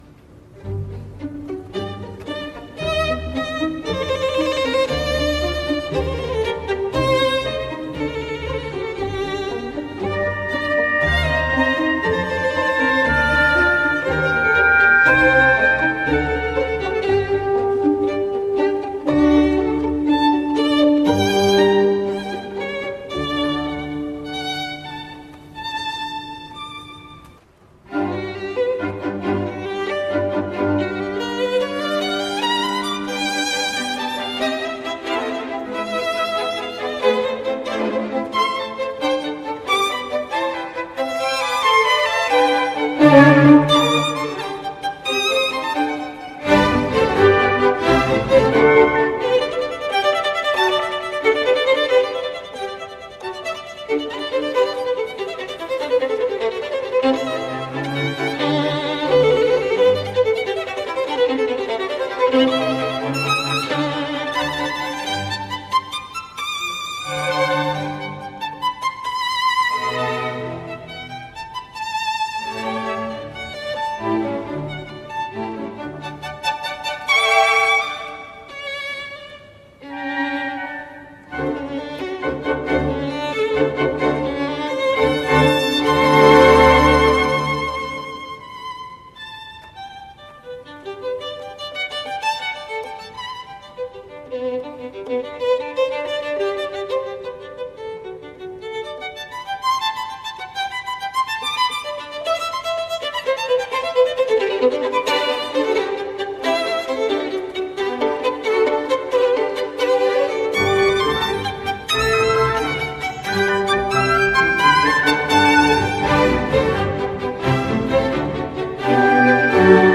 MOZART, concerto pour violon n3, final - MOLENAER (Jan Mlense), le violoniste.mp3